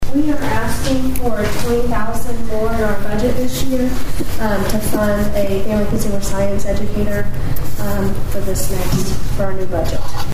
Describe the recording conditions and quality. At Monday's Osage County Commissioners meeting, the Board continued to review the budgets for county offices for the 2024-2025 fiscal year.